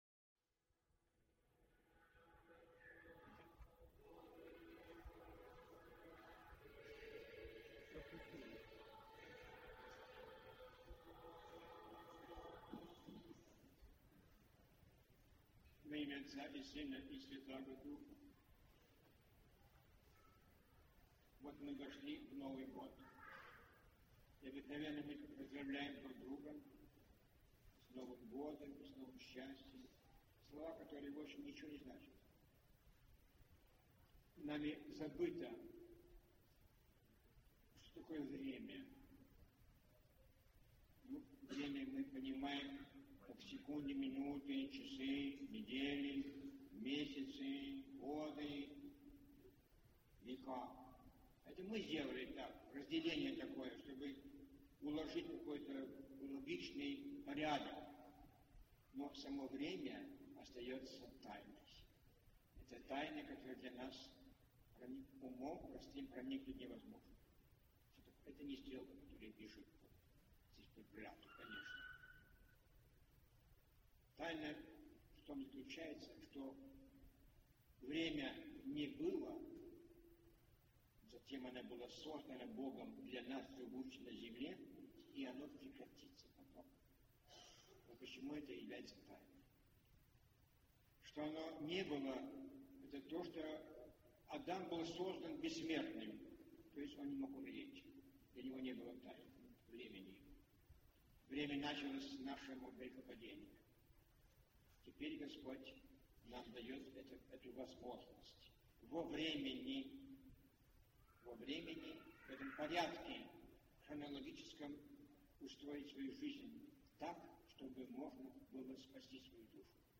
Проповѣдь Святителя Филарета на Рождество Пресвятой Богородицы